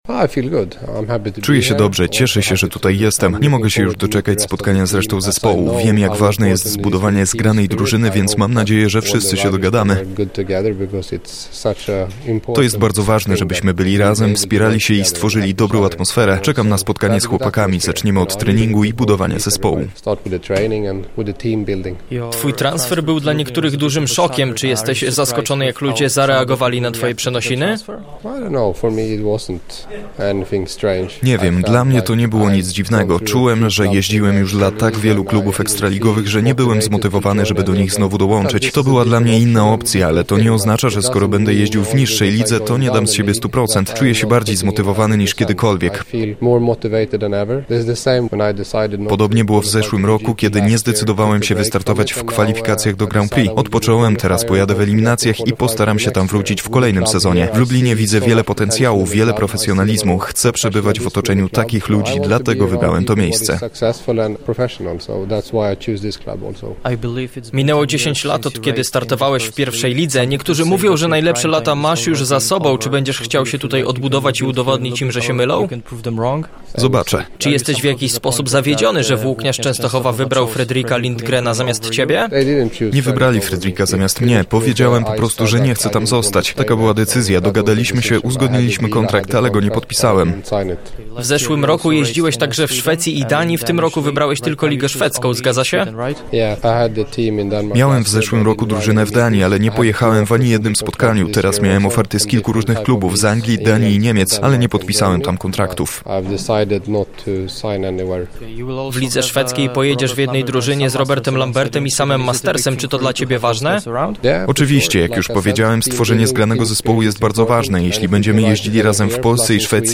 MINIWYWIAD-Andreas-Jonsson-z-tłumaczeniem.mp3